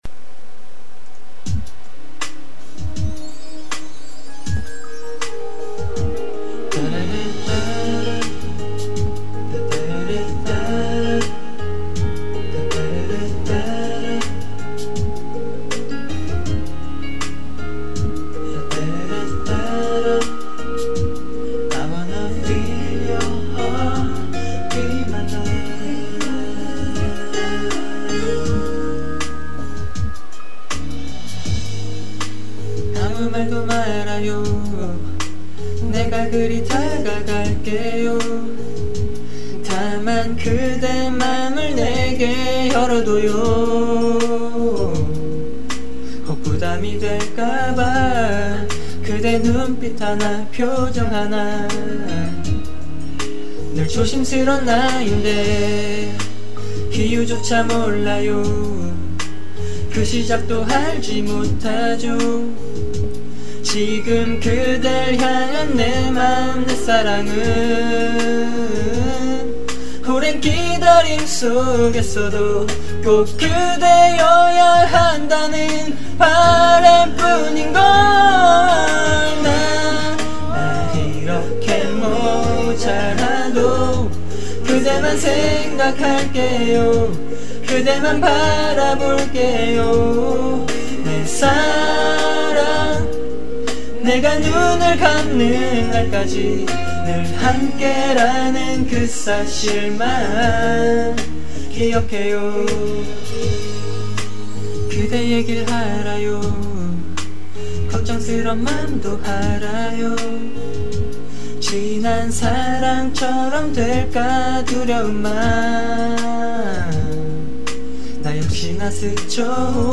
직접 부른 노래를 올리는 곳입니다.